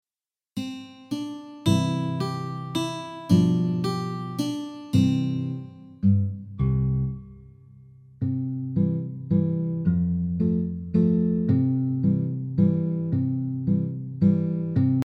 Mp3 Extended Instrumental Track for add-along verses